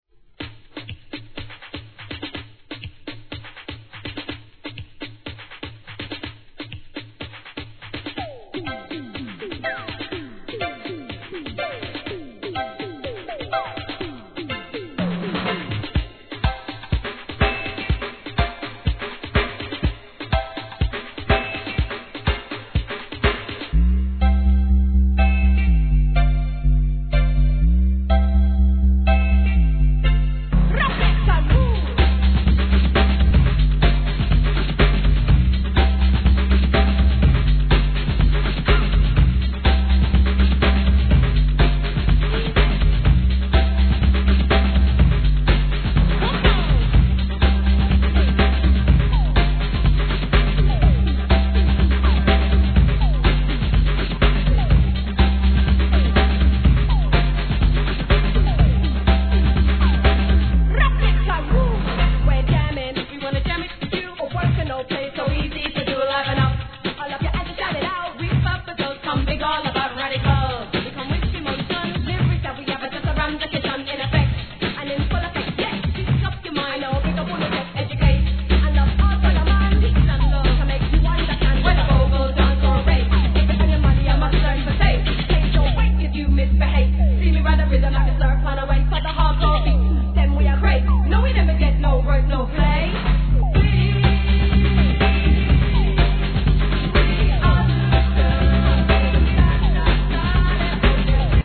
REGGAE
1992年、UK発フィメールコンビによるインディー・ラガHIP HOP!!